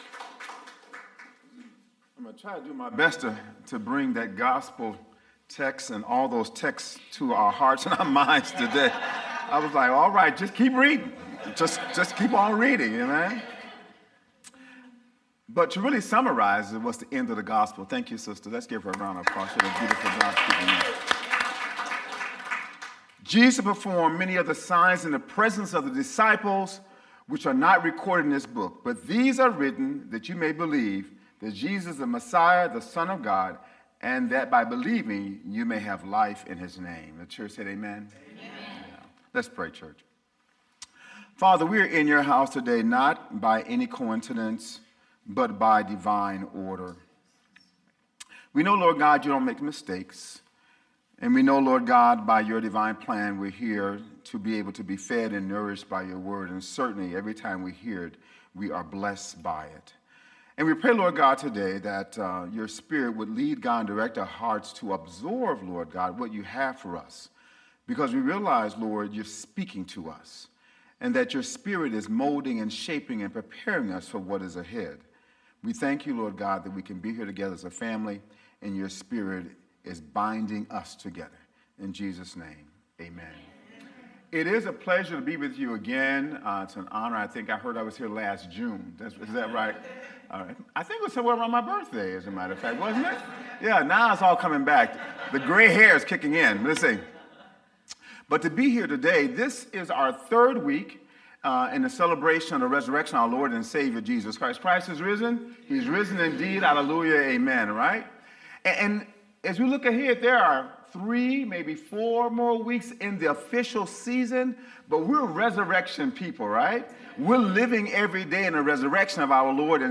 Sermons | Bethel Lutheran Church
May 4 Worship